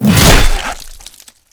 flesh2.wav